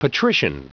Prononciation du mot patrician en anglais (fichier audio)
Prononciation du mot : patrician